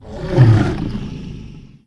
c_hydra_hit1.wav